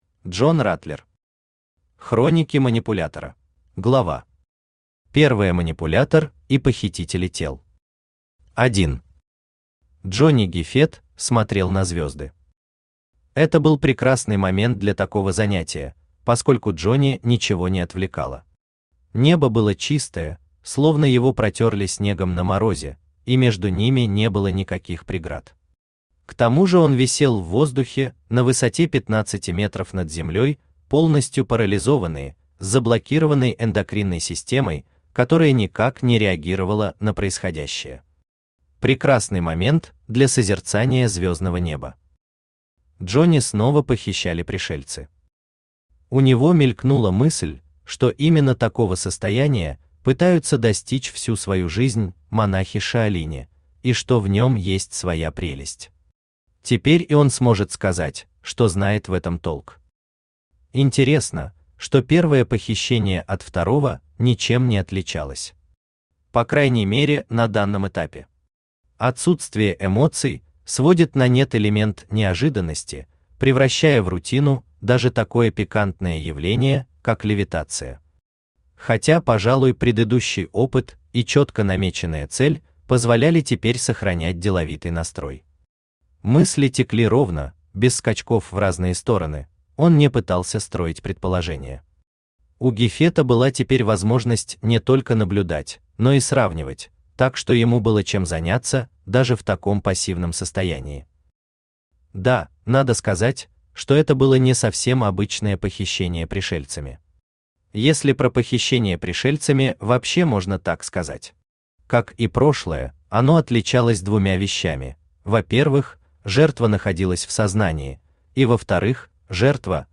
Аудиокнига Хроники Манипулятора | Библиотека аудиокниг
Aудиокнига Хроники Манипулятора Автор Джон Раттлер Читает аудиокнигу Авточтец ЛитРес.